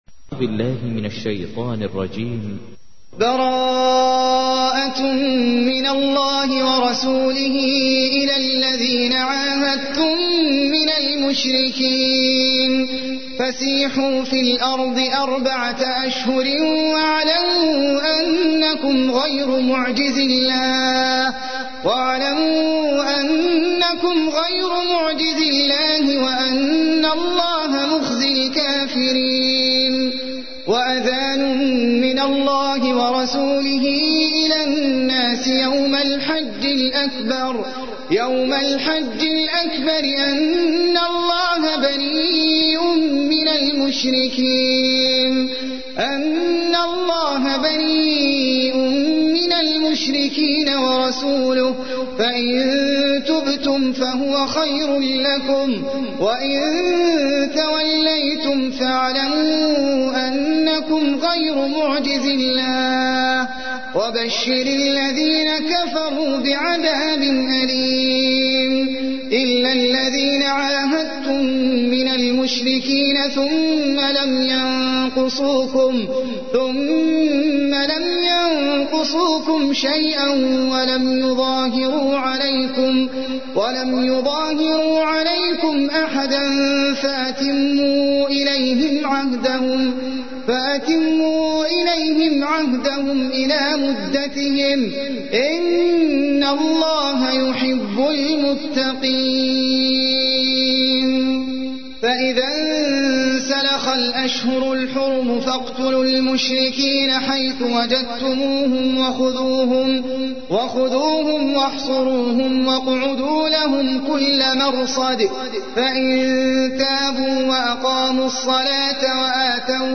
تحميل : 9. سورة التوبة / القارئ احمد العجمي / القرآن الكريم / موقع يا حسين